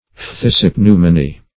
Search Result for " phthisipneumony" : The Collaborative International Dictionary of English v.0.48: Phthisipneumonia \Phthis`ip*neu*mo"ni*a\, Phthisipneumony \Phthis`ip*neu"mo*ny\, n. [NL.